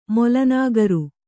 ♪ mola nāgaru